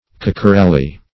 Search Result for " kakaralli" : The Collaborative International Dictionary of English v.0.48: Kakaralli \Kak`a*ral"li\, n. A kind of wood common in Demerara, durable in salt water, because not subject to the depredations of the sea worm and barnacle.